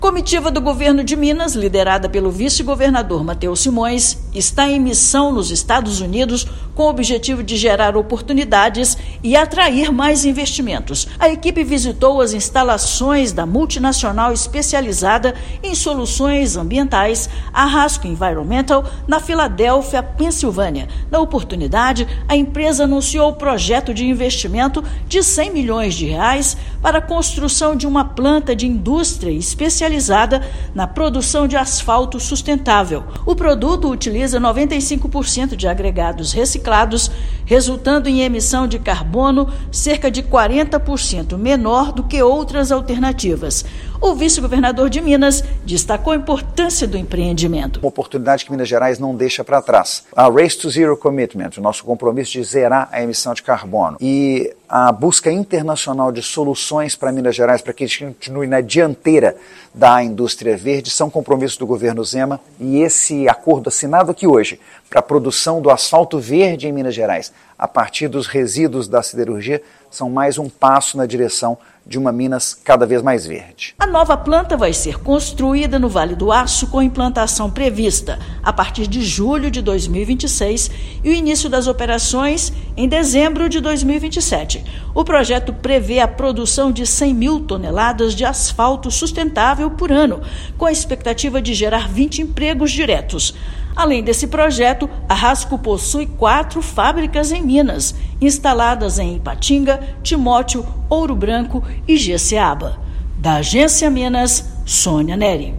[RÁDIO] Governo de Minas e multinacional firmam acordo de R$ 100 milhões para implantação de fábrica de asfalto sustentável no estado